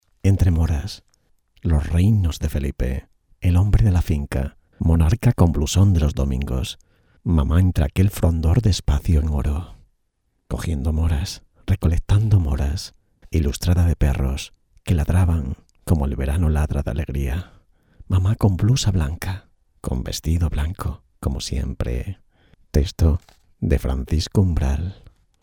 Spanish voiceover.  Spain documentary.